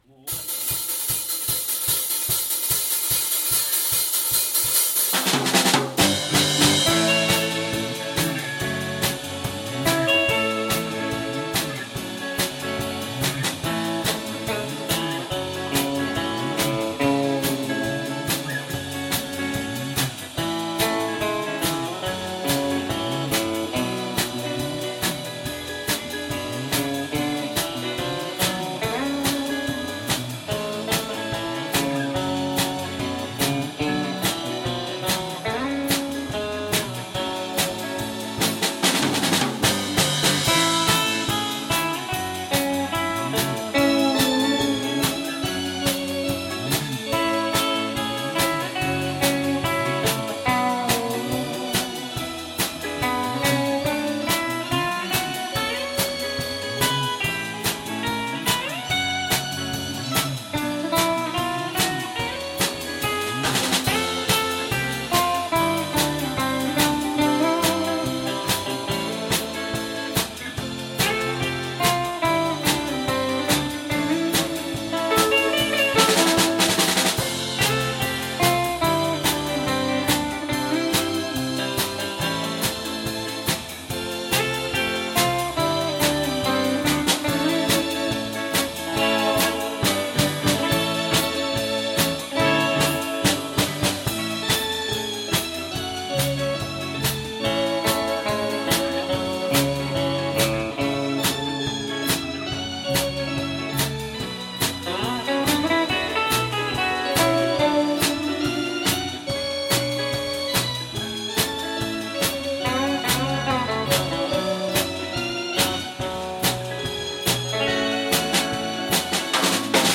10th anniversary Live-1 | The Arou Can